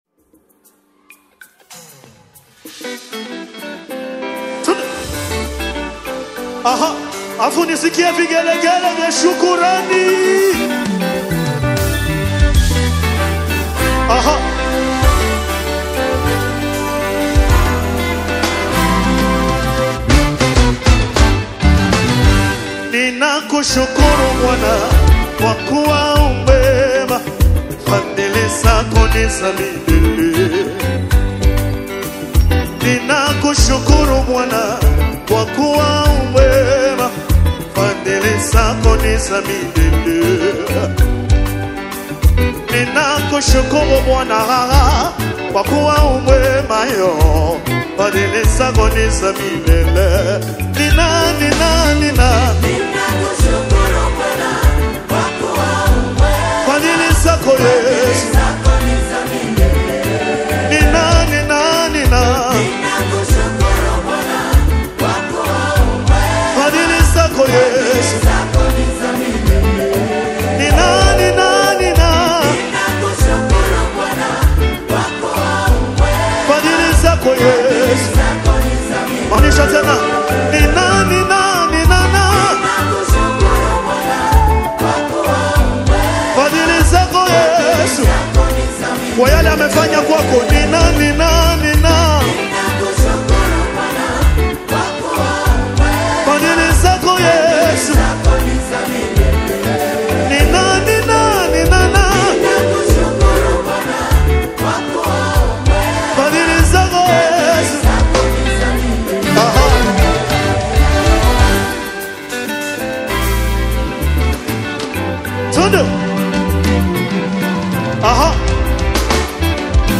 The atmospheric and vocally soaring track
is a "Canyon & Cloud" masterpiece of worship.